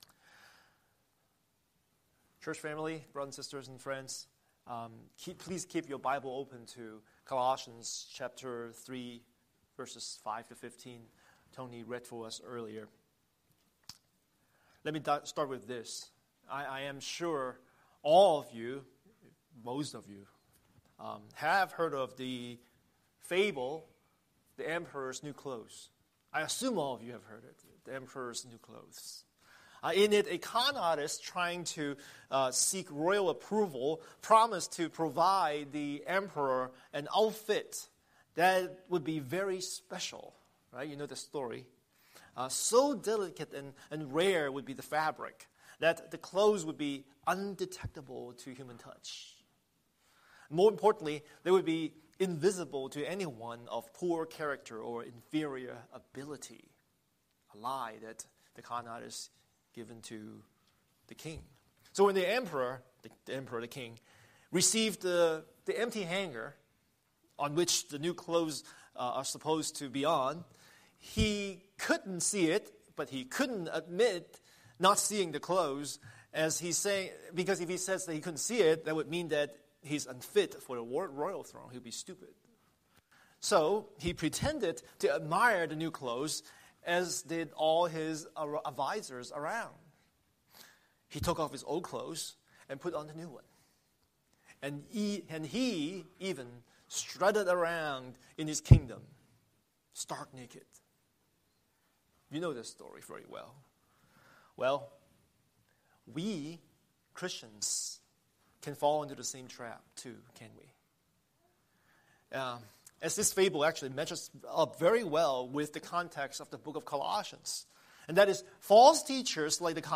Scripture: Colossians 3:5-14 Series: Sunday Sermon